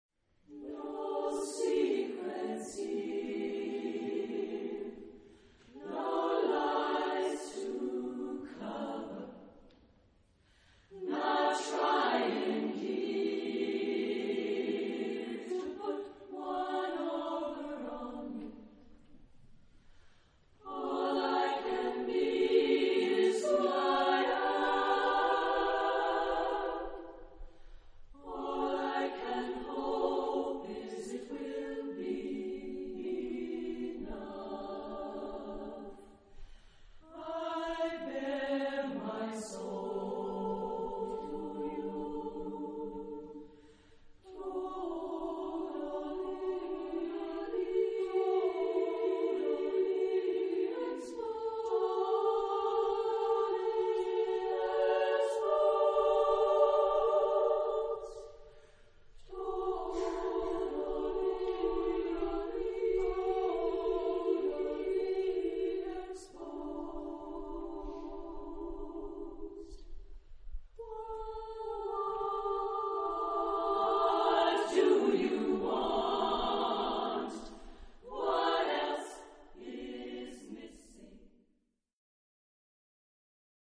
SAA (3 voices children OR women) ; Full score.
Prayer.
Tonality: D major